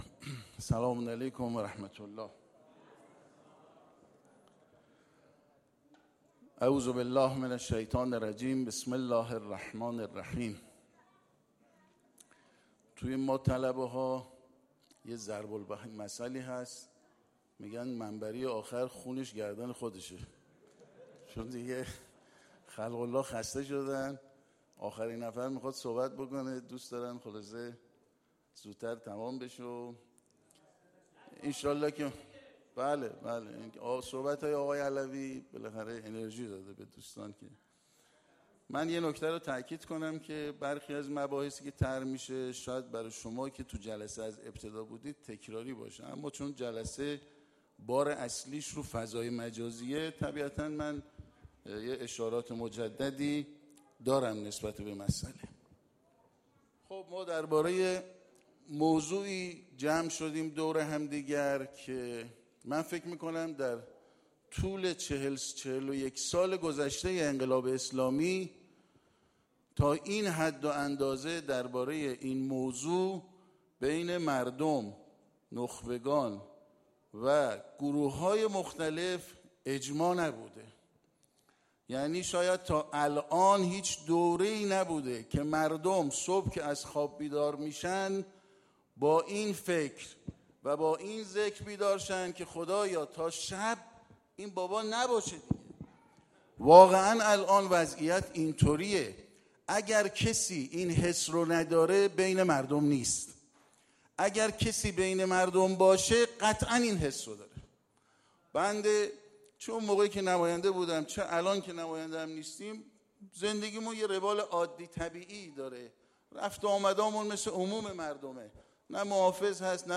دانلود سخنرانی جنجالی حمید رسایی در مورد استیضاح روحانی، مذاکره، خیانت، مجلس جدید و…